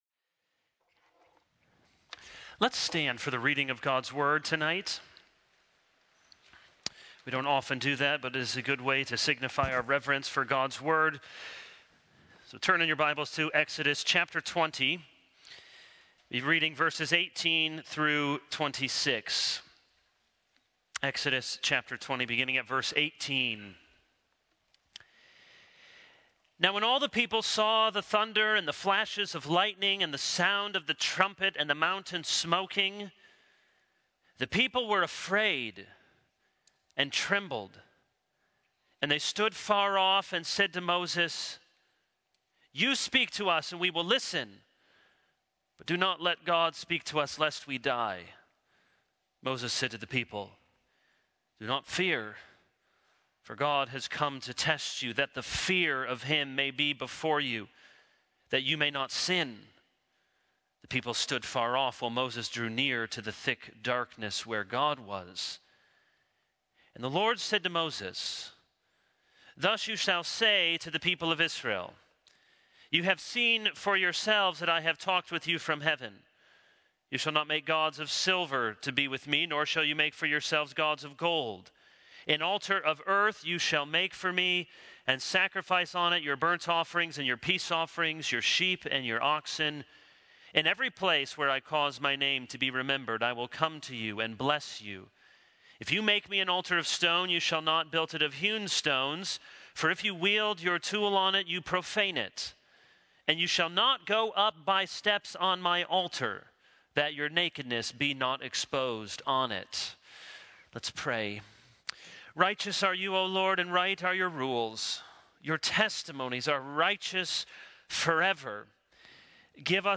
This is a sermon on Exodus 20:18-26.